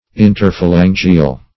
Search Result for " interphalangeal" : The Collaborative International Dictionary of English v.0.48: Interphalangeal \In`ter*pha*lan"ge*al\, a. (Anat.)